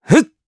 Neraxis-Vox_Jump_jp.wav